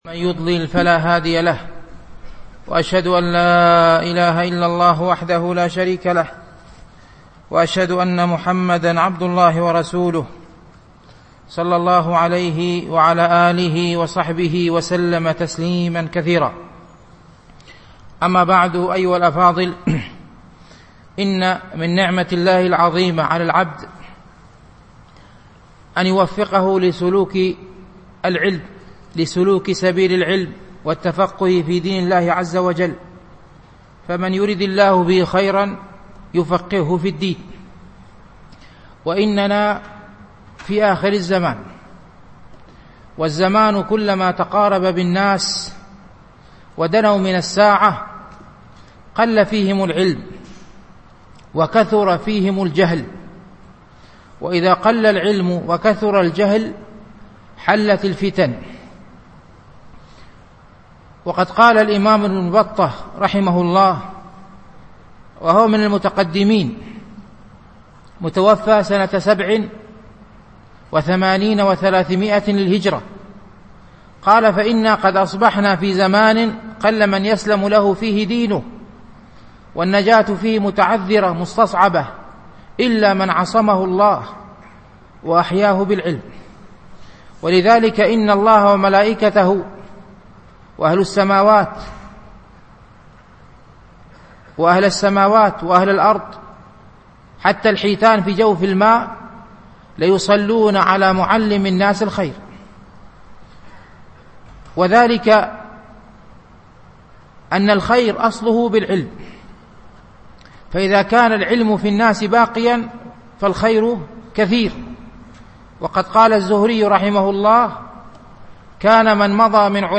القسم: الحديث